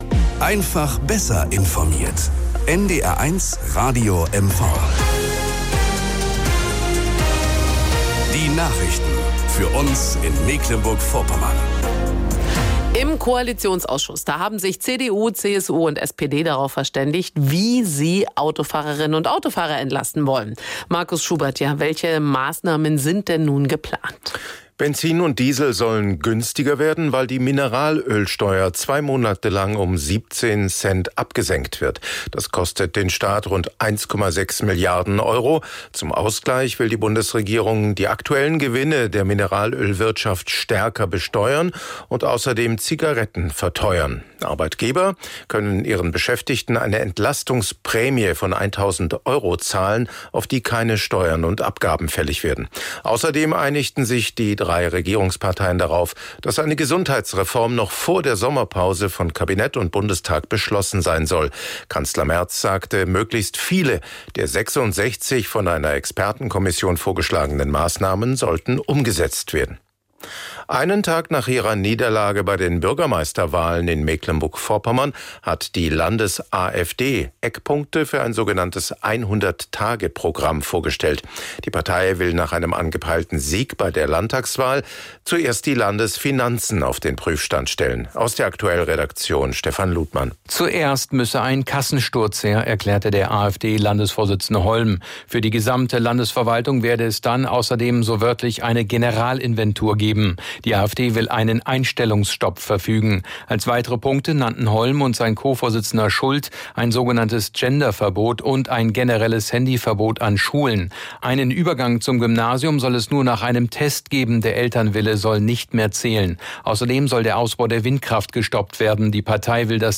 Nachrichten und Informationen aus Mecklenburg-Vorpommern, Deutschland und der Welt von NDR 1 Radio MV.